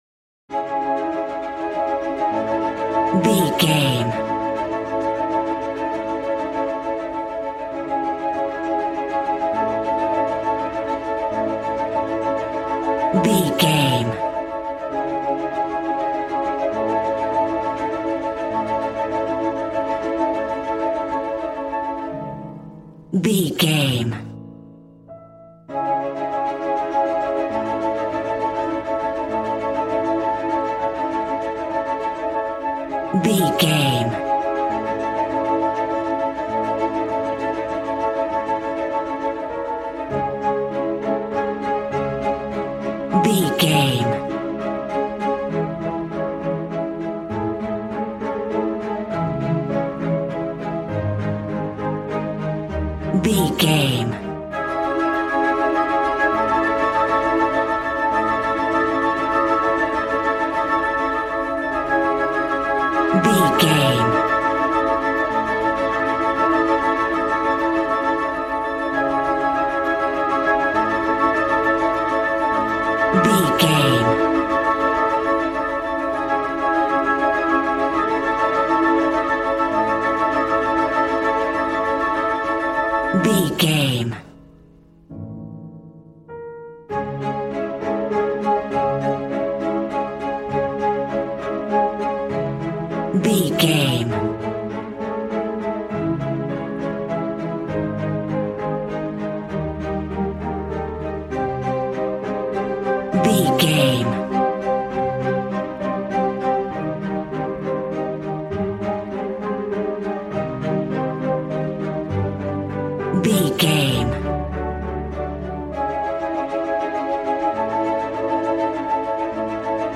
Regal and romantic, a classy piece of classical music.
Ionian/Major
strings
violin
brass